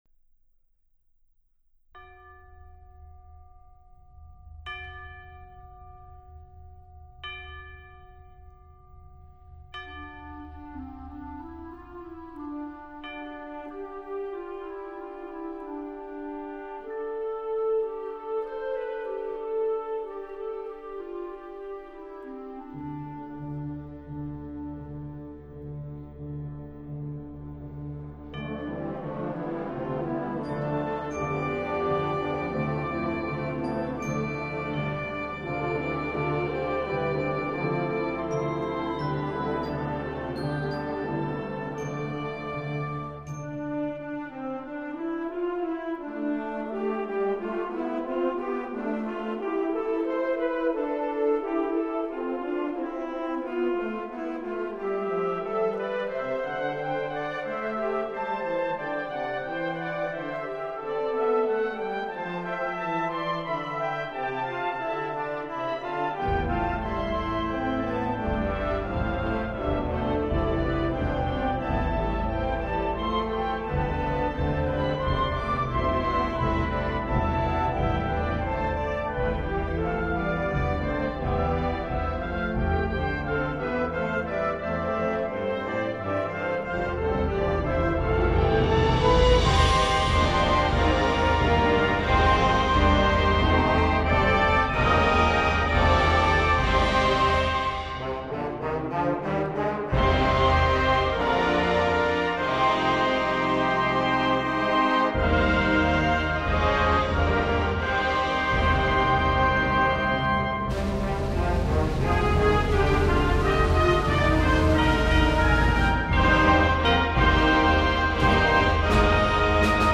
Genre: Band
Piccolo
Oboe
Bassoon
Bass Clarinet
Tenor Saxophone
Baritone Saxophone
Tuba
Timpani (4 drums)
Percussion 1 (snare drum, bass drum, suspended cymbal)